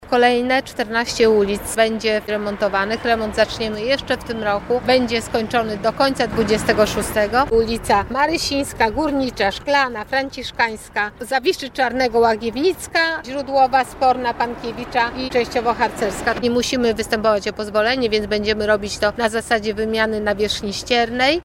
Po modernizacji Wojska Polskiego zaczną się kolejne remonty na Bałutach Jak mówi prezydent Miasta Łodzi Hanna Zdanowska, po zakończeniu modernizacji Wojska Polskiego rozpoczną się kolejne remonty na Bałutach.